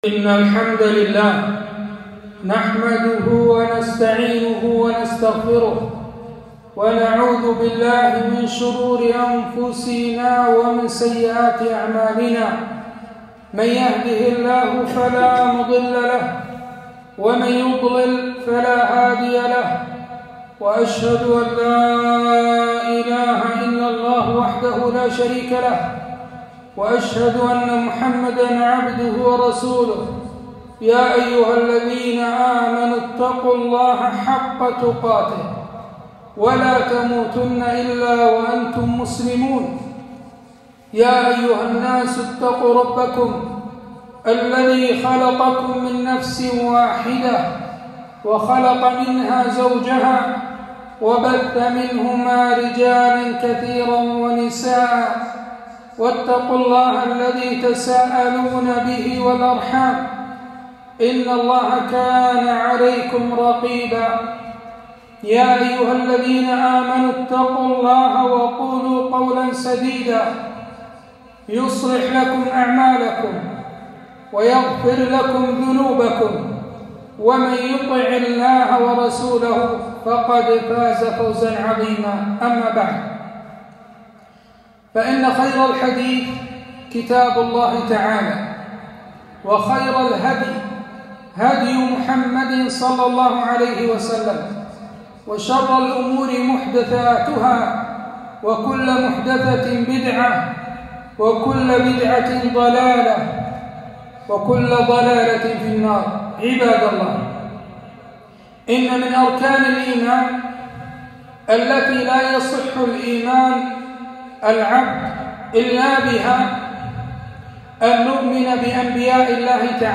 خطبة - نبي الله عيسى بن مريم عليه السلام